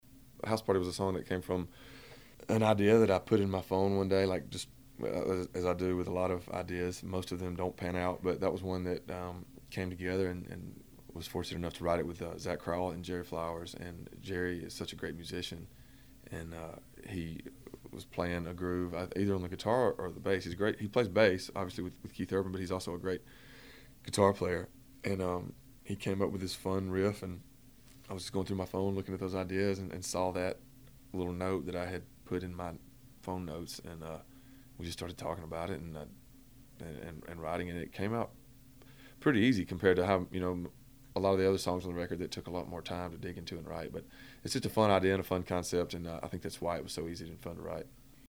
Audio / Sam Hunt explains how he and his cowriters came up with the idea for “House Party.”